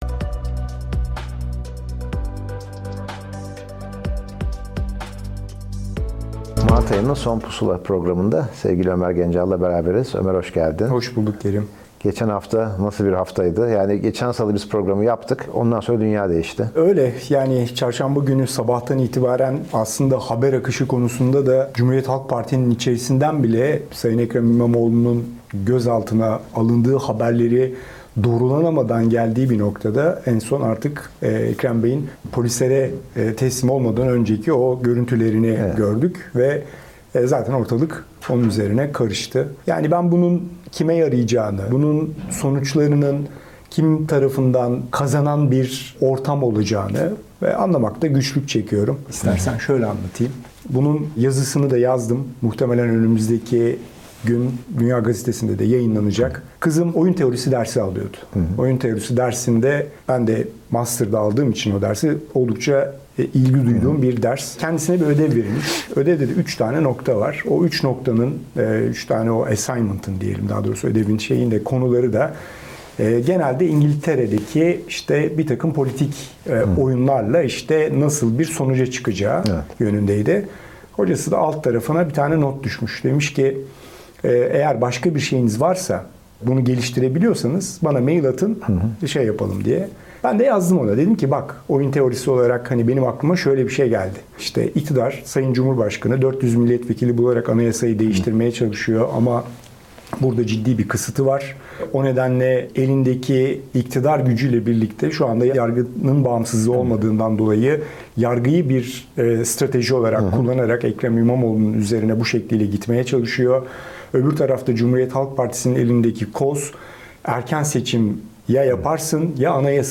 kritik haftada piyasaların nasıl açıldığını ve bundan sonrası olası hareketleri canlı yayında konuşuyoruz. Borsa, dolar ve faizdeki son durumu değerlendiriyoruz.